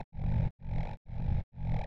• 129 breathe bass 2008 - Em.wav